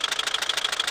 ExtensionLadderExtend.ogg